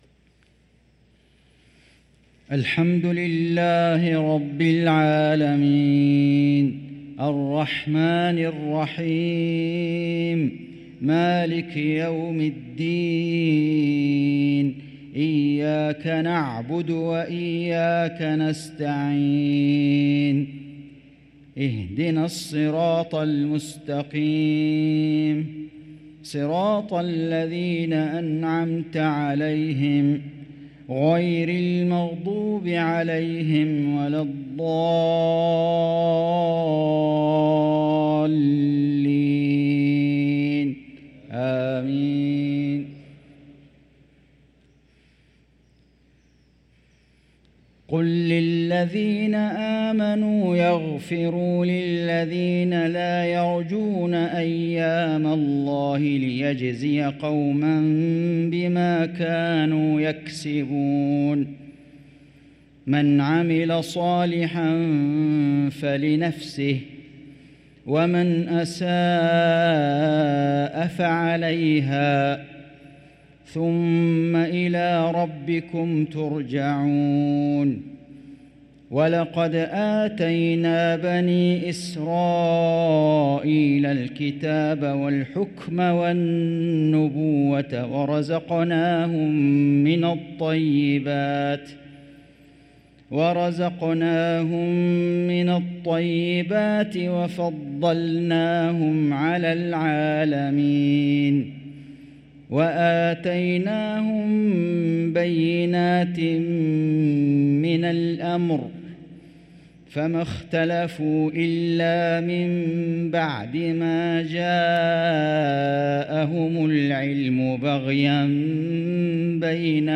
صلاة المغرب للقارئ فيصل غزاوي 2 صفر 1445 هـ